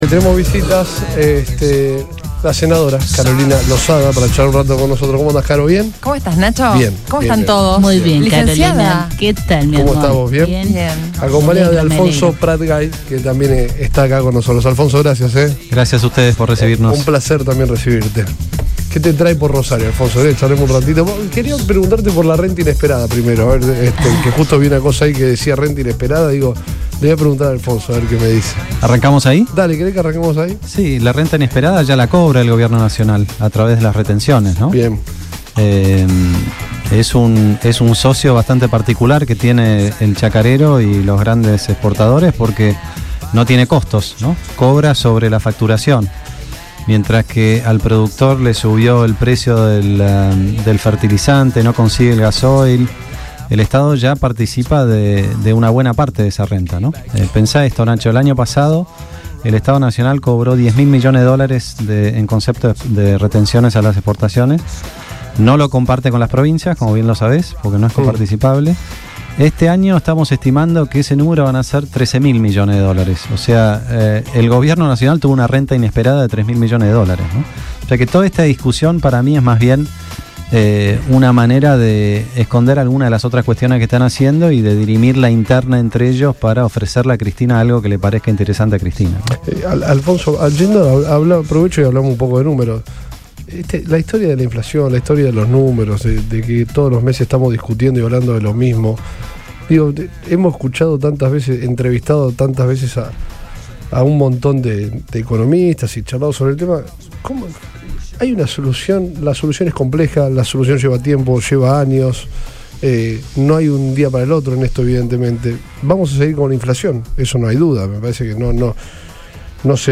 Alfonso Prat-Gay, ex presidente del Banco Central, visitó los estudios de Radio Boing junto a Carolina Losada, senadora de Juntos por el Cambio.